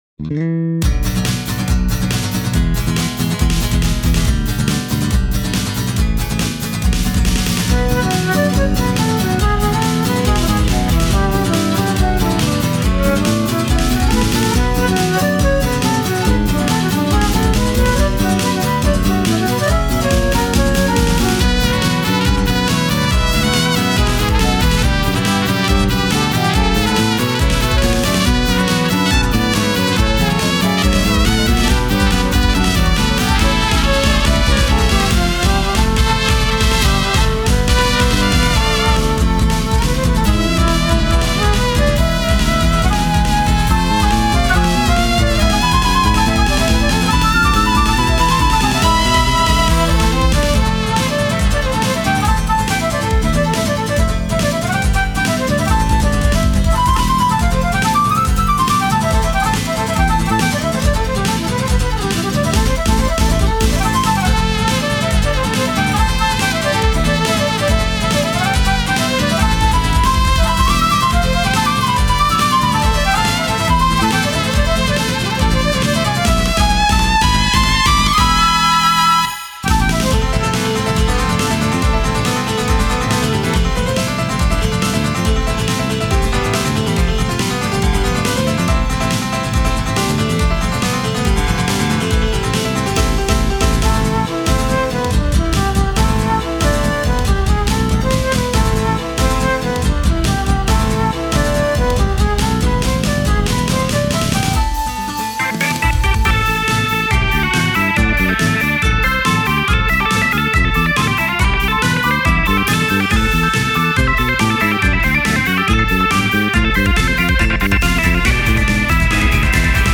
TOUHOU FIGHTER STYLETOUHOU STYLE